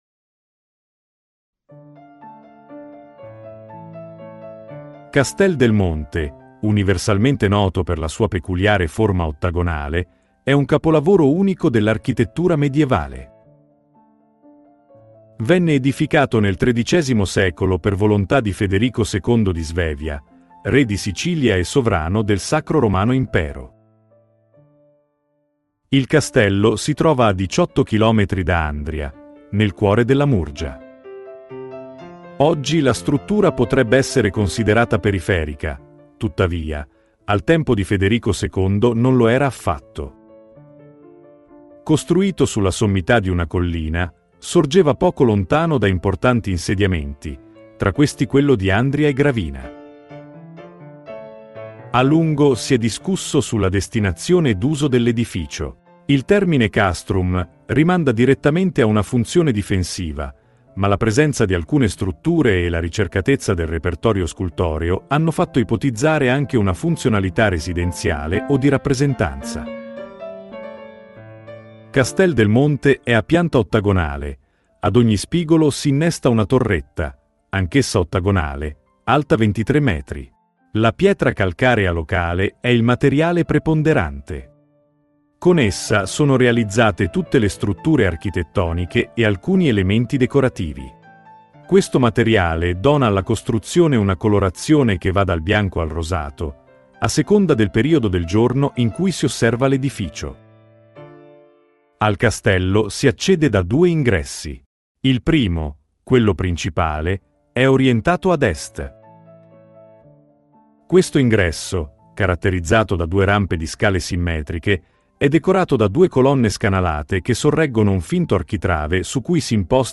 Audioguida Castel del Monte